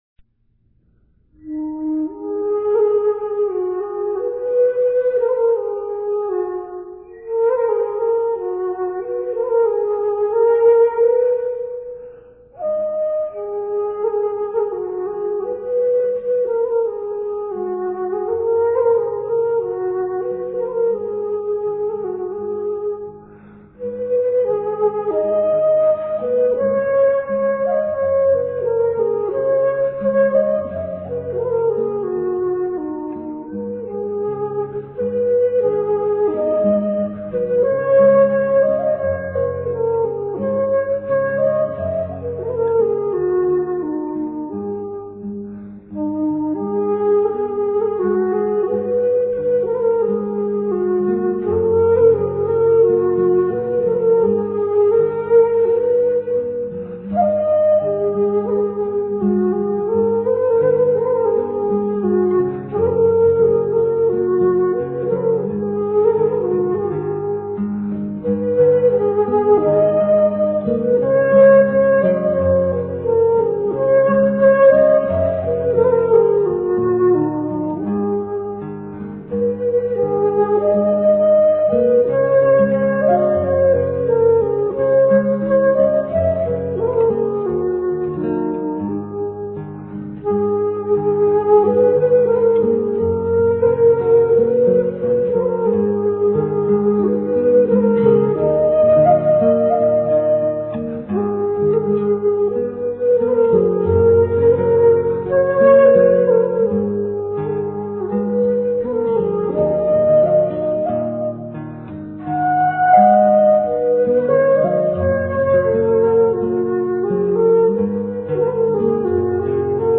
Here are a few short folk melodies I have composed.
A song with middle-eastern flavor. In the 500-Kbyte MP3 file, this haunting tune is played by my duo,   Dobhran ,   in a set with an ancient English dance melody called Goddesses.